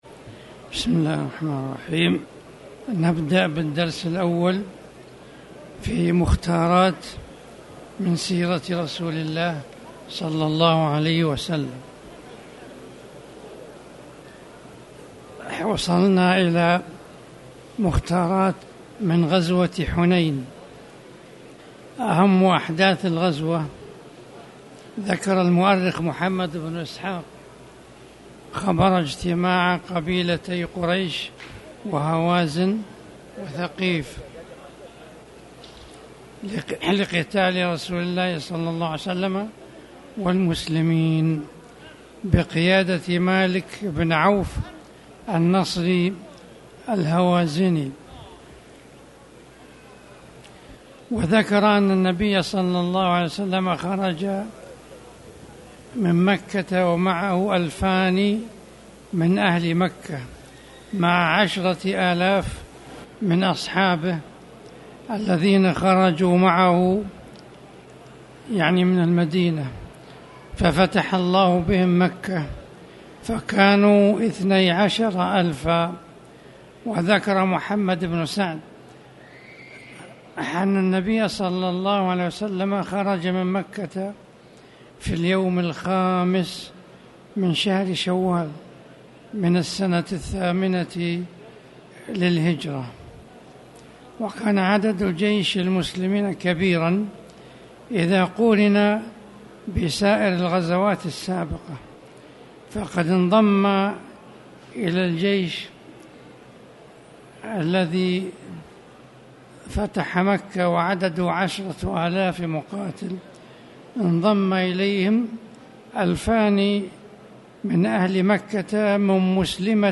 تاريخ النشر ٩ ذو القعدة ١٤٣٩ هـ المكان: المسجد الحرام الشيخ